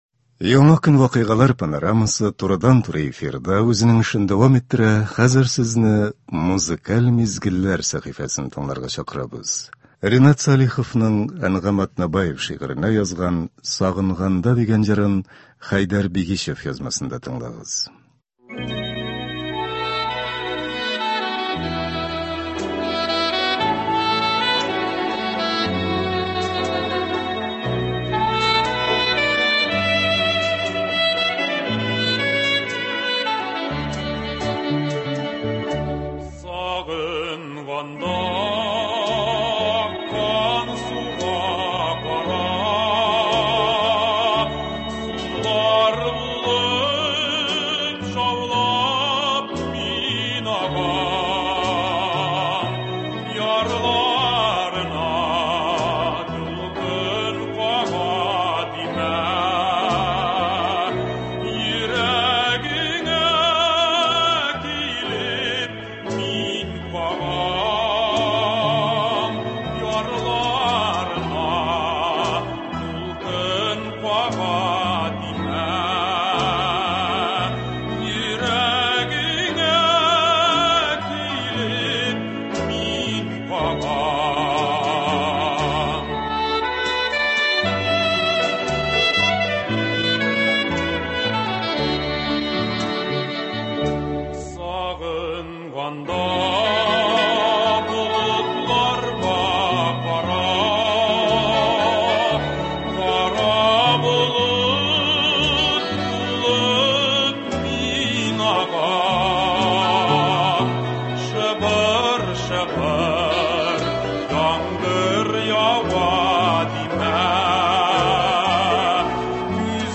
халкыбызның яраткан җырлары яңгырый.